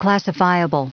Prononciation du mot classifiable en anglais (fichier audio)
Prononciation du mot : classifiable